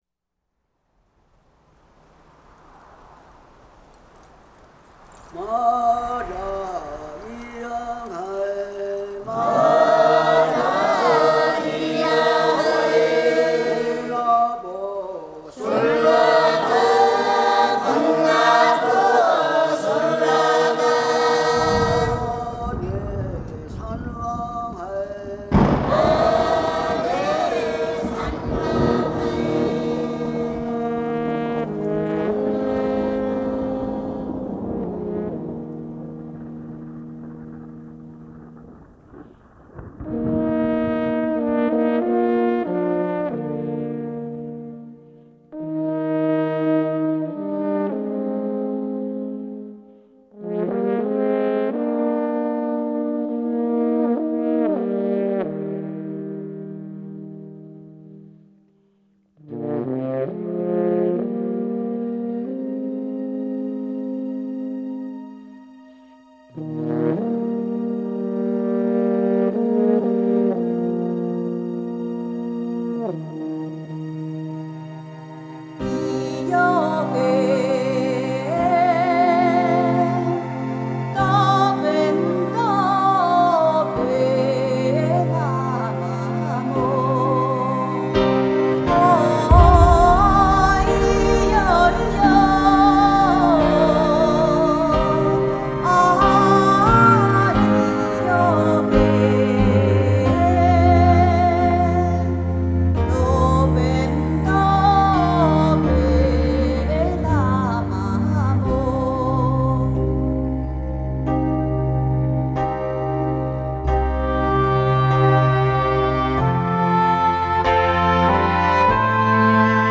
钢琴、竖琴、大提琴、手风琴、爱尔兰笛
融合布农、南邹部落人声与克尔特乐风